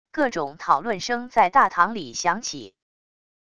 各种讨论声在大堂里响起wav音频